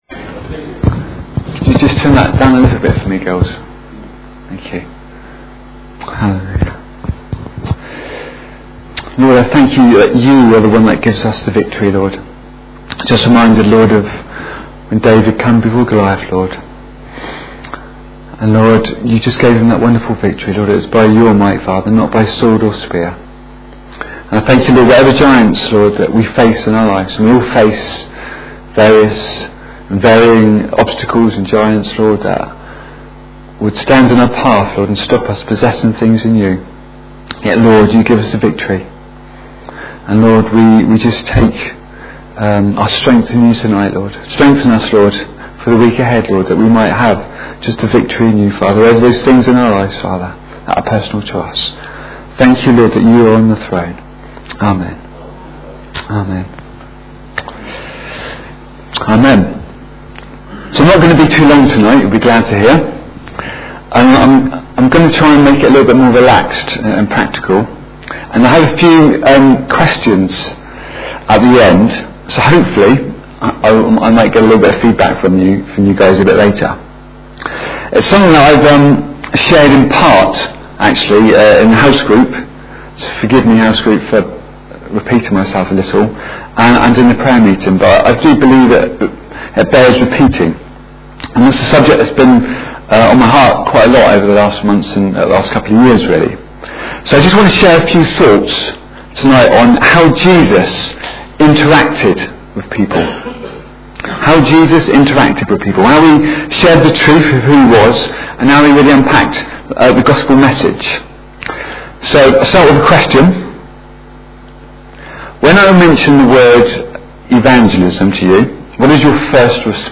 Sermons Archive - Page 30 of 31 - Calvary Pentecostal Church